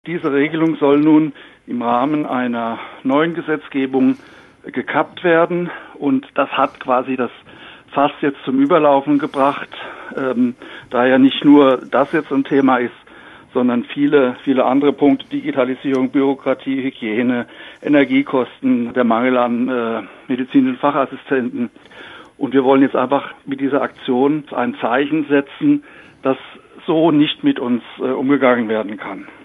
Interview zu Ärzte-Protestaktion